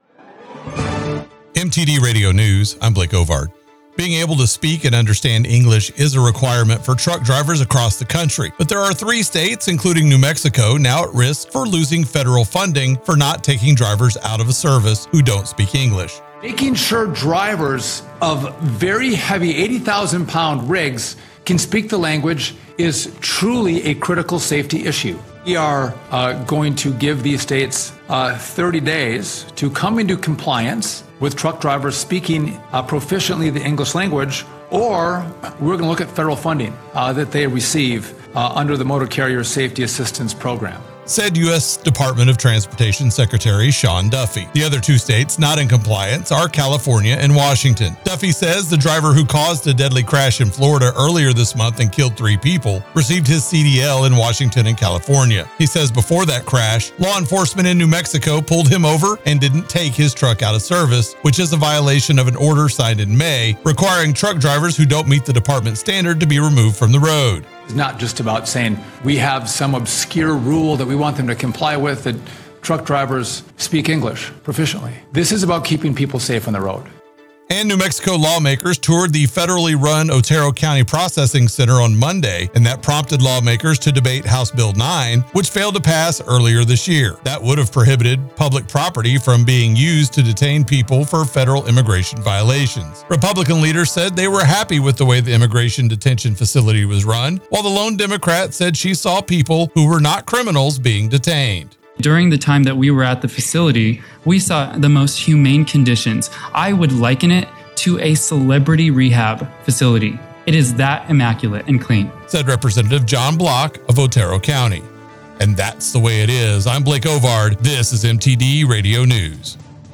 KBUY News – Ruidoso and New Mexico